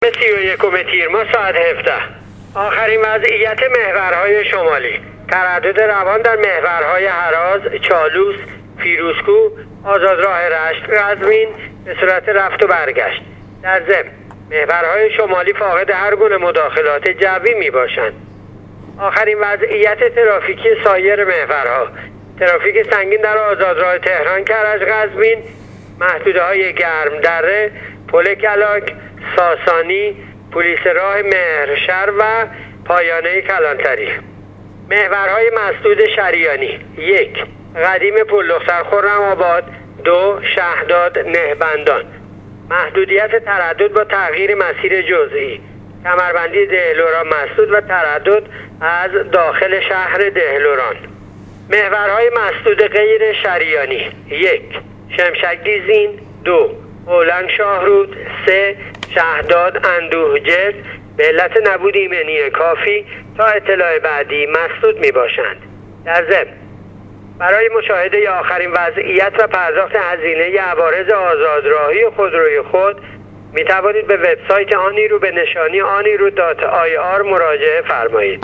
گزارش رادیو اینترنتی وزارت راه و شهرسازی از آخرین وضعیت‌ ترافیکی راه‌های کشور تا ساعت ۱۷ سی و یکم تیرماه/ترافیک سنگین در محورهای تهران-کرج-قزوین